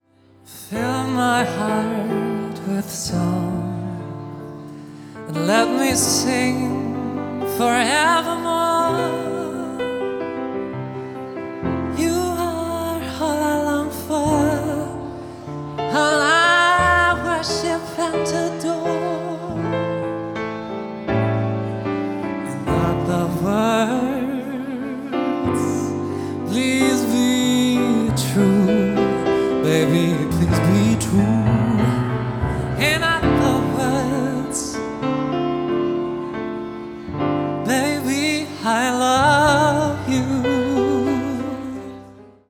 live von der celebrations Hochzeitsmesse Frankfurt